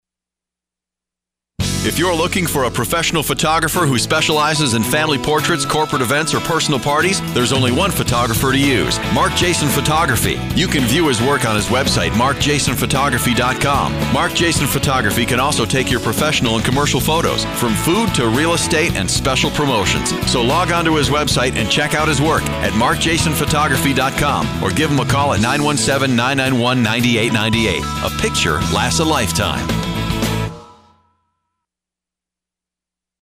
Radio Advertisement
commercial_spot.mp3